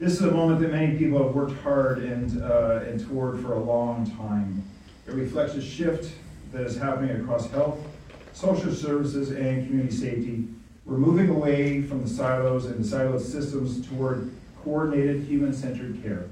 A grand opening ceremony for the Renfrew County Mesa HART Hub was held on Thursday, February 5th